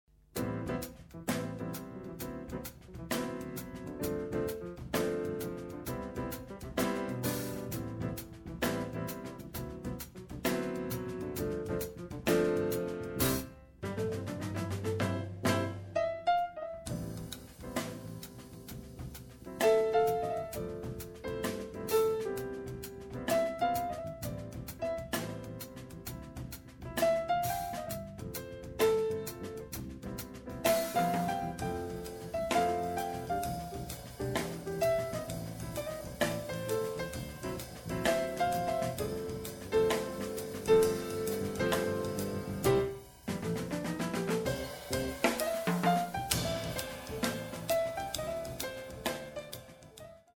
Détail combo: version trio: piano, basse et batterie.